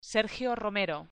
Guía de Pronunciación BRASIL 2014
ES_Sergio_ROMERO.mp3